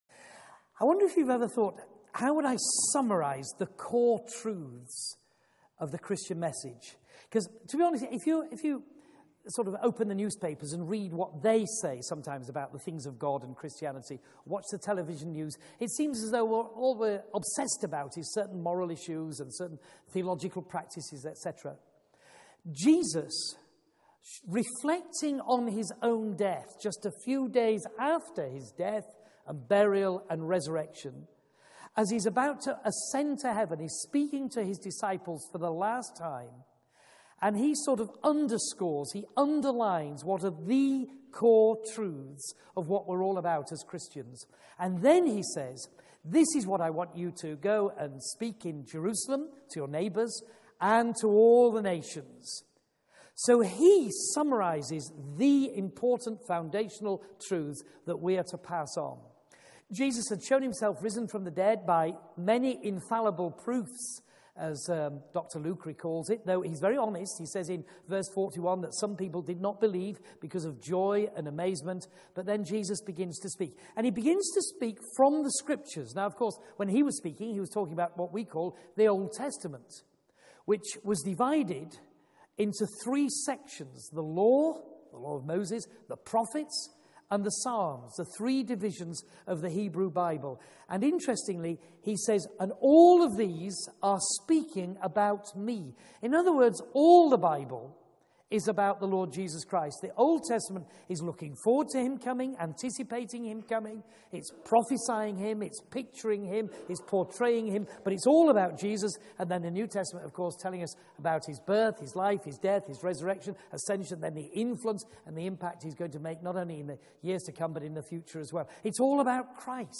Media for 9:15am Service on Sun 08th Jun 2014 09:15 Speaker